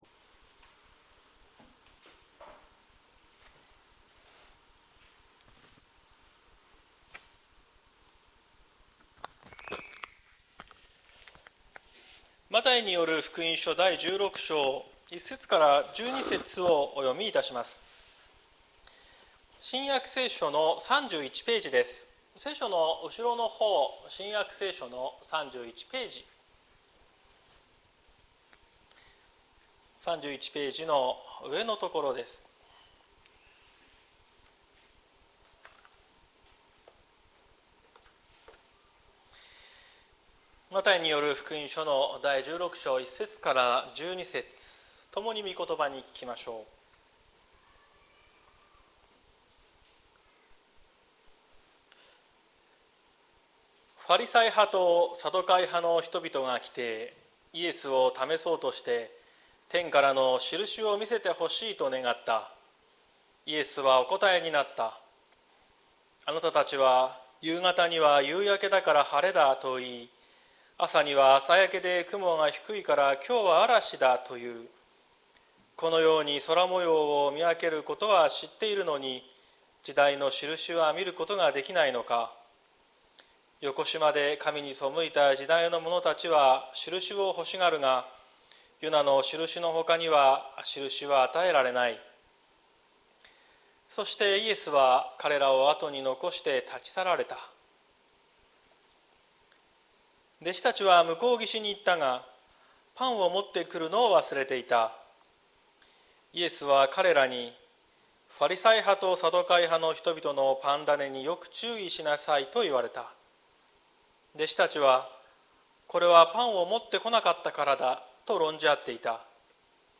2024年08月11日朝の礼拝「批判者を退けるイエス」綱島教会
綱島教会。説教アーカイブ。